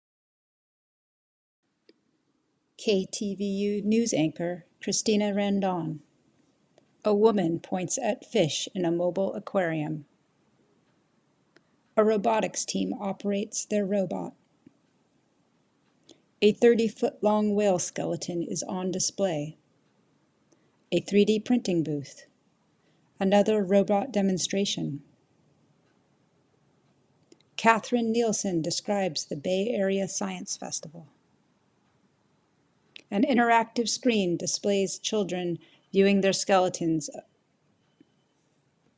TV COVERAGE of BASF 2025 at UCSF Mission Bay